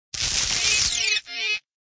SRobotShock.ogg